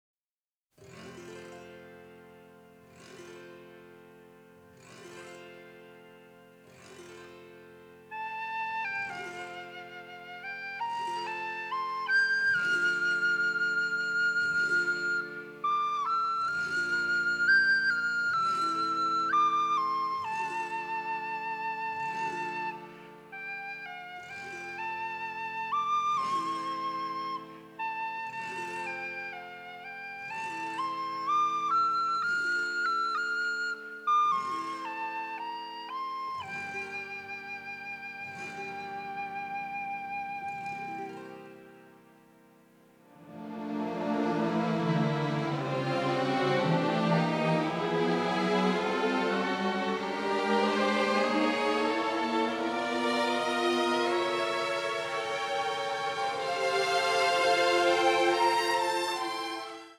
a sensitive, dramatic, delicate score with an Irish flavor